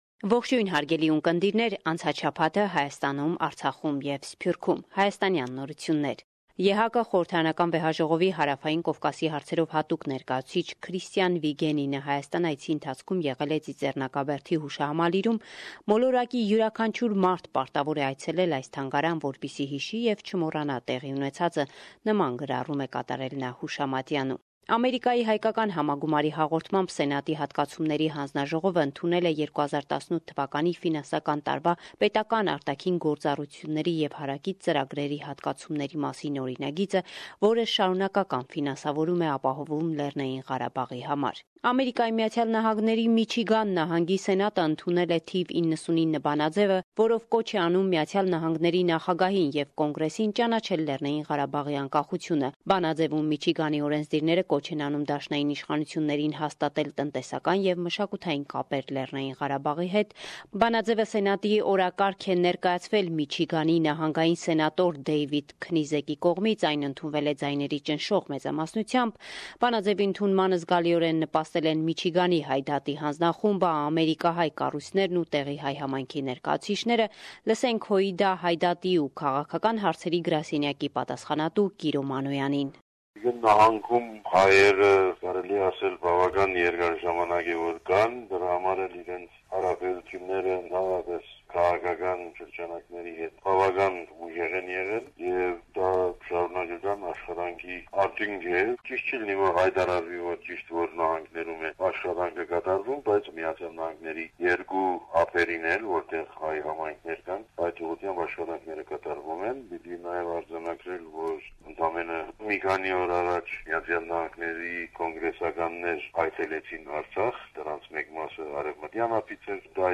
Վերջին լուրերը - 3/10/2017